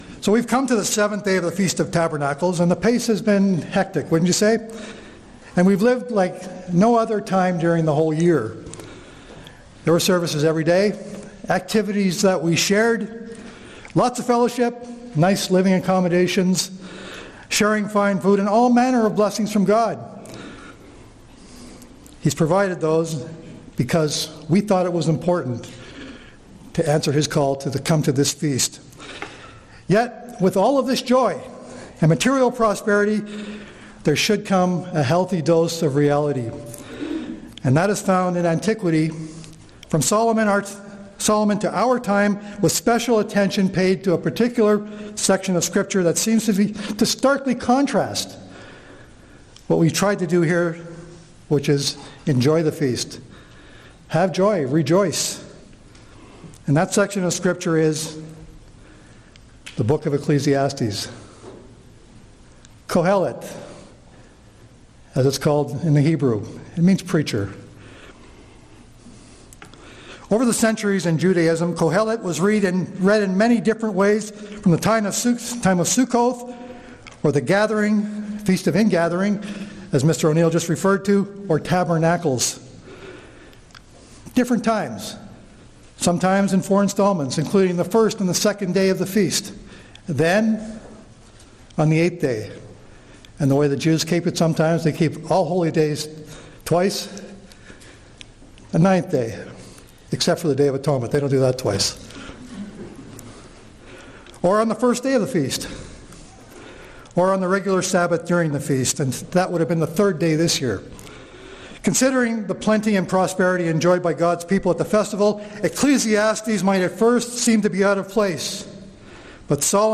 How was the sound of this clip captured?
Midland 2024